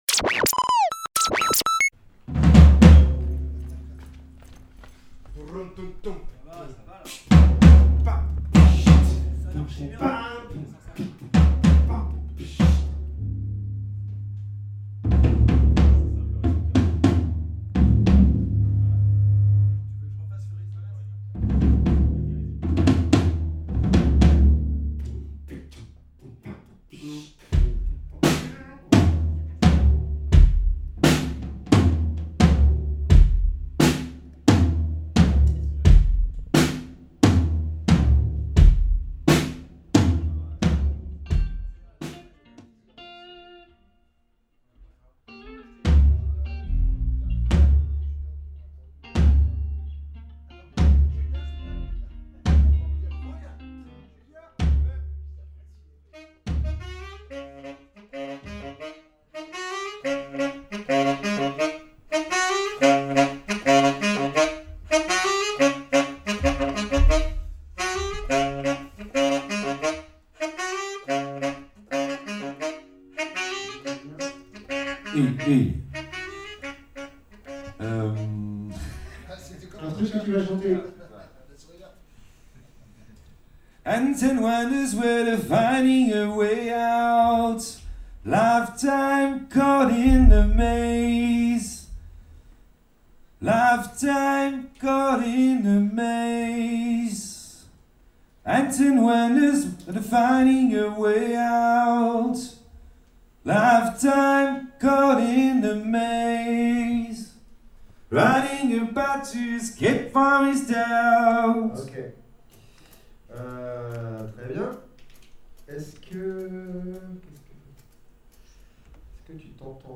Pour préparer le Ouest Park, plusieurs artistes investissent les studios du CEM, au fort de Tourneville, le temps d’une résidence.
c’est un hip-hop funky et céleste né au Havre.
Leur flow en anglais, porté par une voix soul, se développe sur des beats ciselés et organiques.
Dans cet épisode, on s’immerge au cœur de leur résidence : une séance de travail intense pour peaufiner le live qui sera présenté au Ouest Park la semaine suivante.